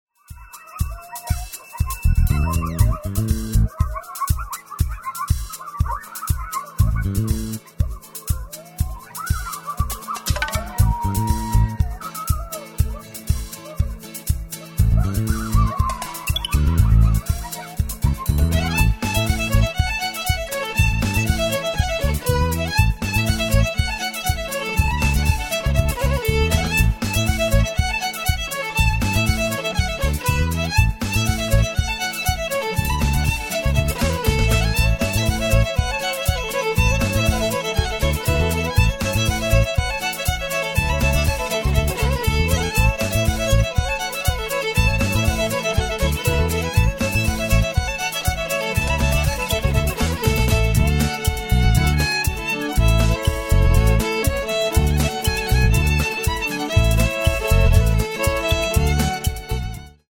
Скрипка во все времена считалась танцевальным инструментом.